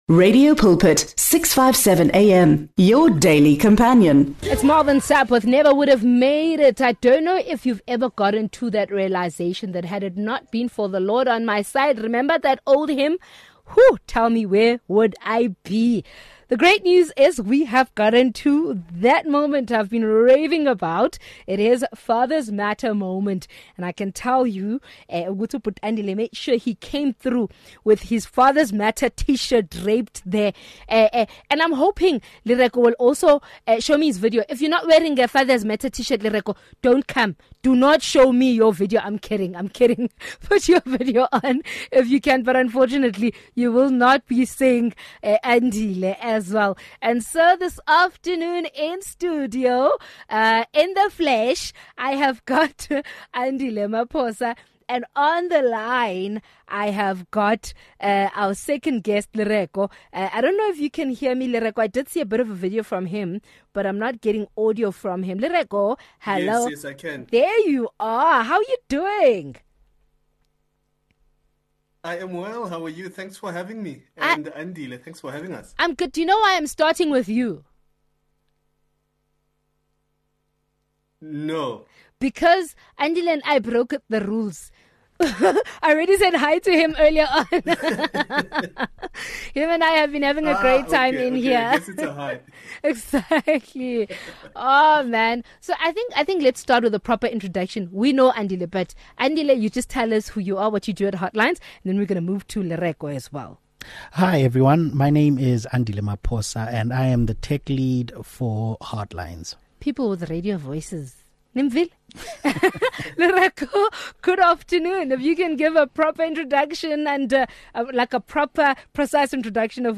They highlight the importance of positive fatherhood and the impact fathers have on families and communities. The conversation encourages men to be present, supportive, and engaged in their children’s lives, promoting stronger, healthier relationships.